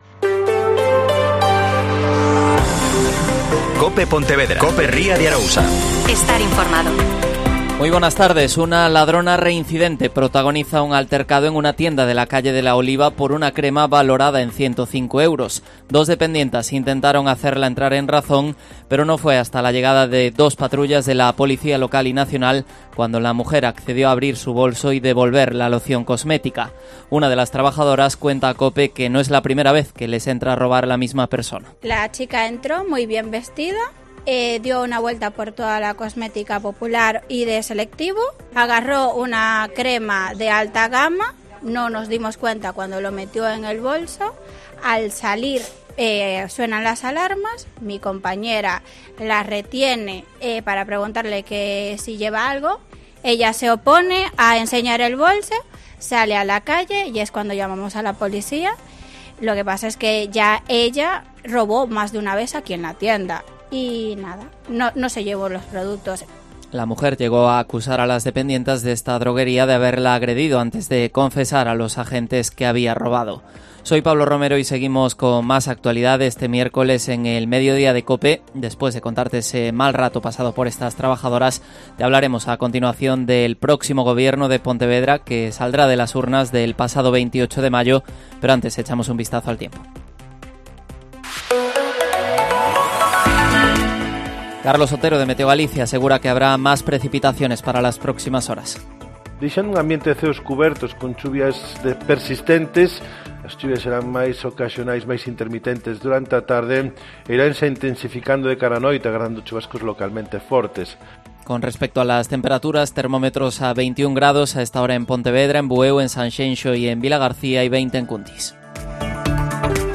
Mediodía COPE Pontevedra (Informativo 14:20h)